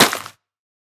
latest / assets / minecraft / sounds / block / muddy_mangrove_roots / step4.ogg
step4.ogg